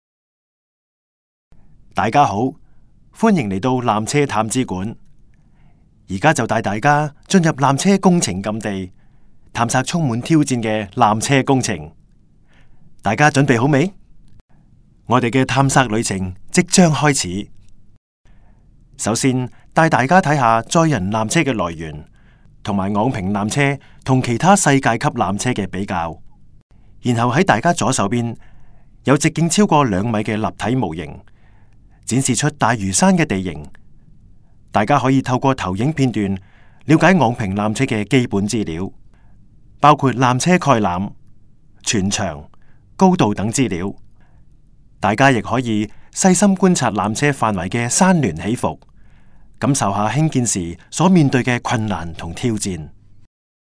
纜車探知館語音導賞 (廣東話)